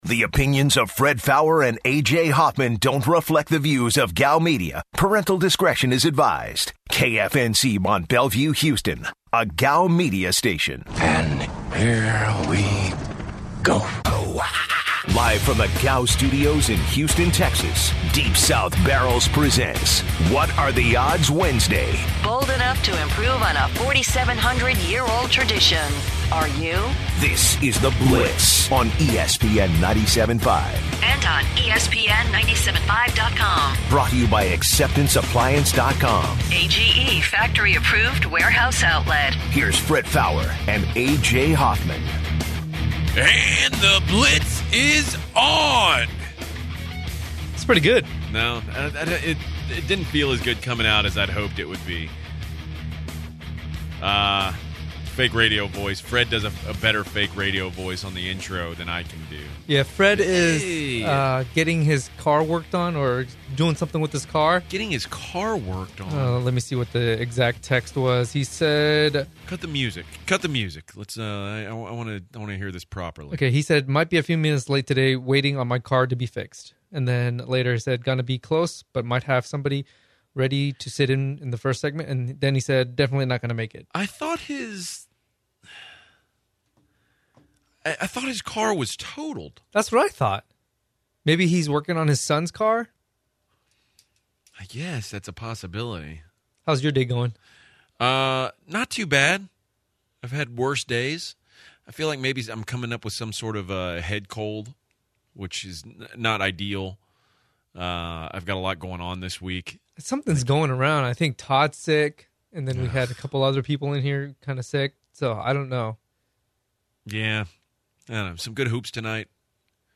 The guys take some calls on What are the Odds Wednesday regarding the Texans QB situation, the future of online poker, and Peyton Manning.